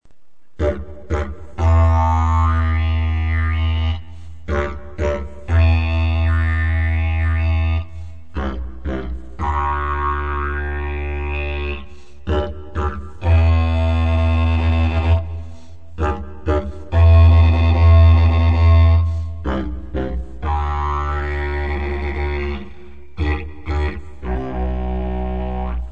Material: Plaste ;-)
Tonart: H(164 cm)-G(95 cm)